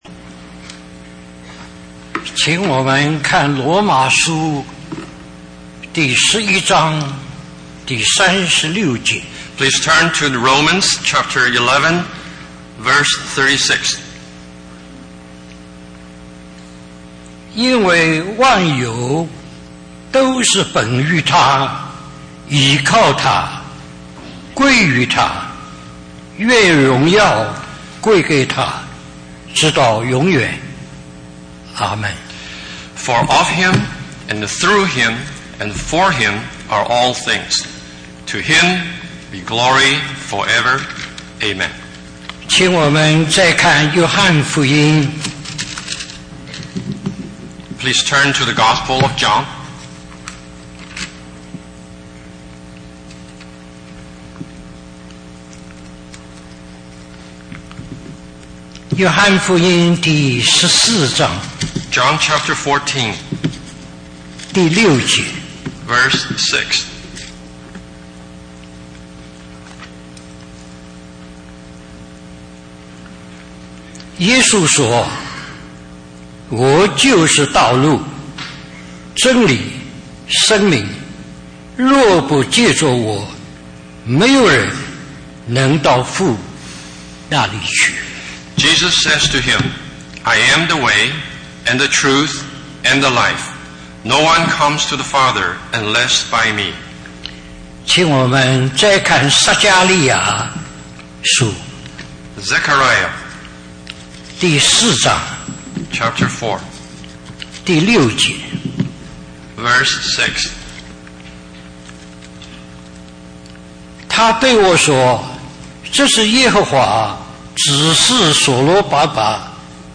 A collection of Christ focused messages published by the Christian Testimony Ministry in Richmond, VA.
Special Conference For Service, Taipei, Taiwan